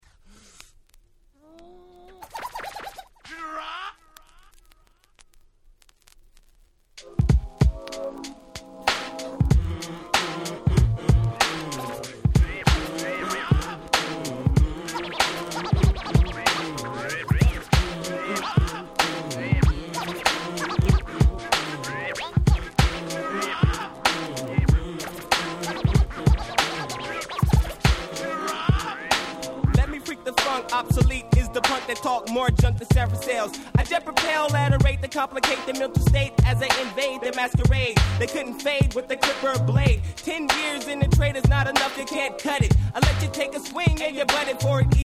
95' Big Hit Hip Hop.